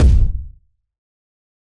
Kick (11).wav